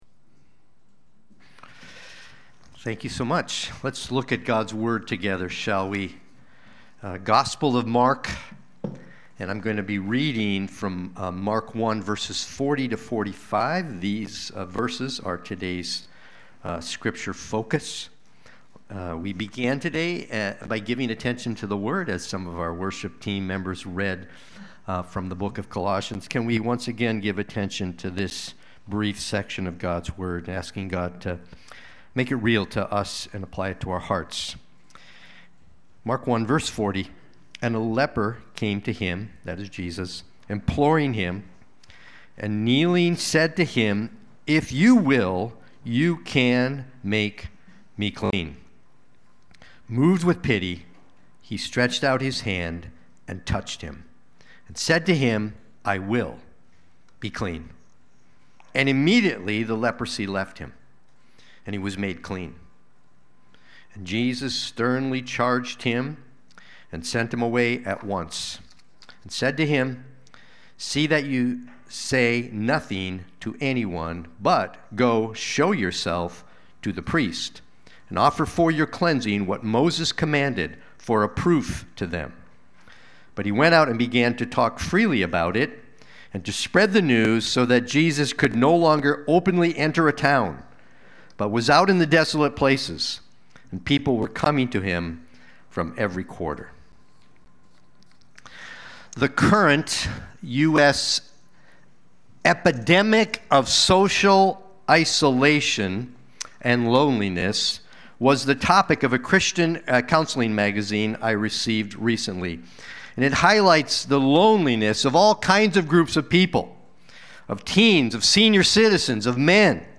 Watch the replay or listen to the sermon.
Sunday-Worship-main-11225.mp3